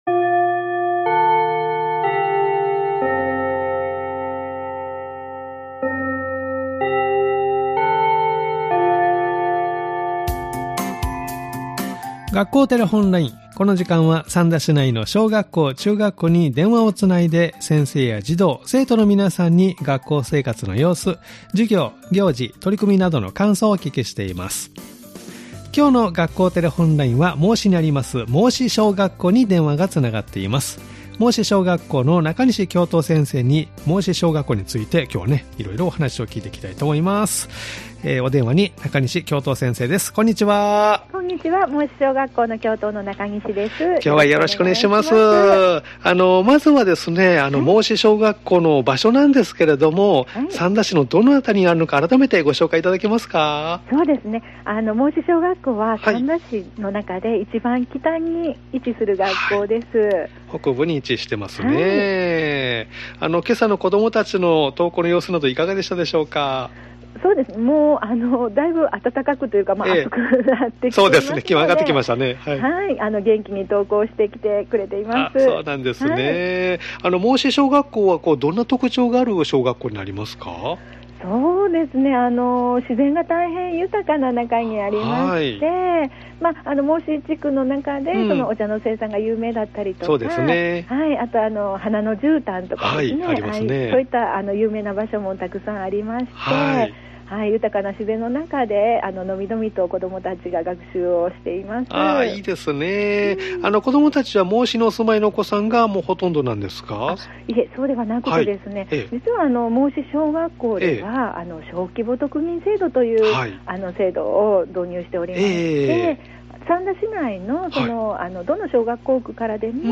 （再生ボタン▶を押すと番組が始まります） 「学校テレフォンライン」では三田市内の小学校、中学校に電話をつないで、先生や児童・生徒の皆さんに、学校生活の様子、授業や行事、取り組みなどの感想をお聞きしています！